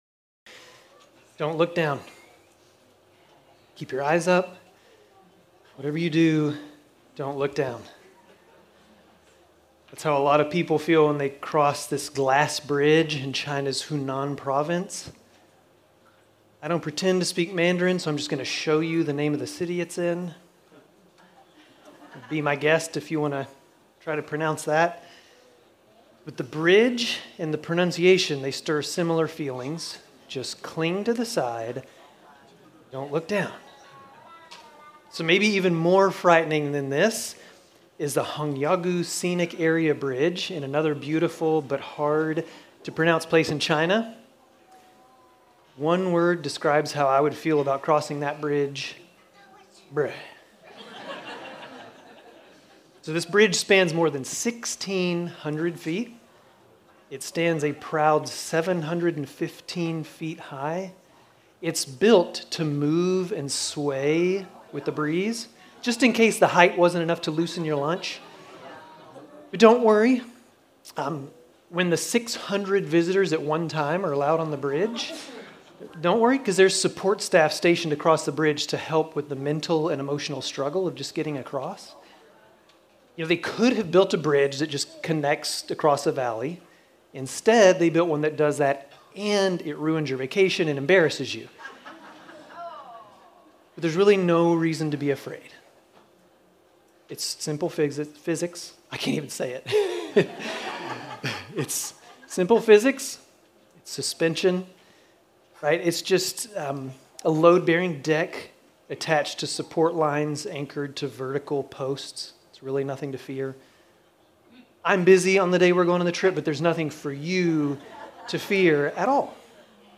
Grace Community Church Dover Campus Sermons 7_13 Dover Campus Jul 14 2025 | 00:28:59 1x 00:00 / 00:28:59 Subscribe Share RSS Feed Share Link Embed